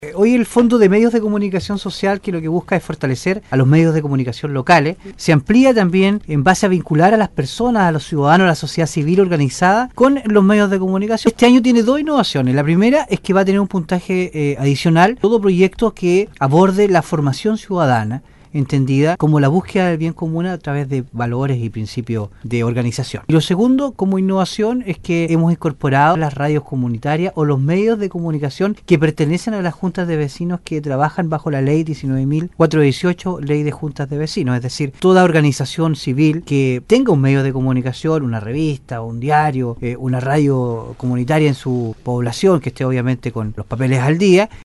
Así mismo lo ratificó el Seremi de gobierno Francisco Reyes, quien en conversación con radio Sago indicó que este año el concurso trae dos importantes novedades enfocadas a los medios comunitarios que son creados y desarrollados por organizaciones sociales, así también se otorgará mayor puntaje a los programas que promuevan la Educación Cívica o Ciudadana.